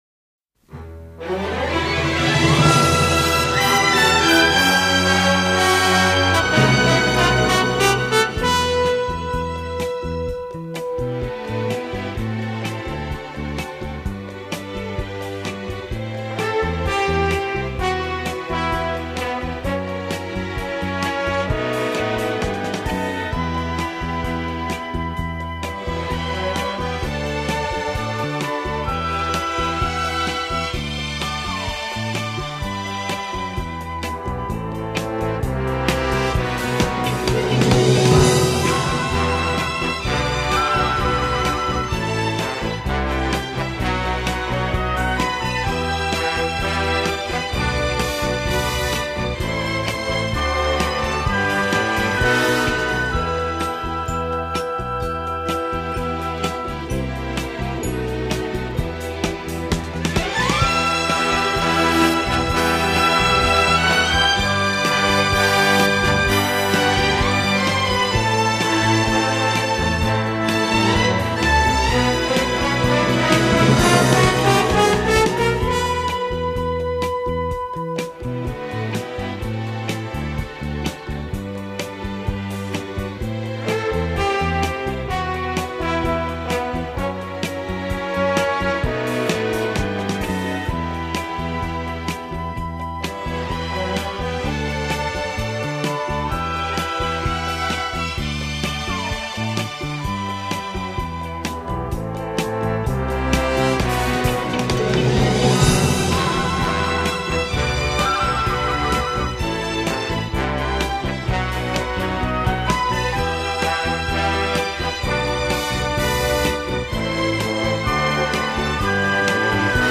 (カラオケ)